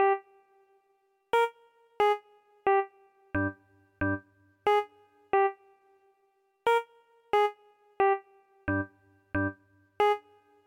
90 器官 2
Tag: 90 bpm Hip Hop Loops Organ Loops 1.79 MB wav Key : Unknown